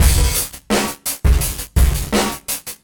伞形环路变形
Tag: 82 bpm RnB Loops Drum Loops 490.32 KB wav Key : Unknown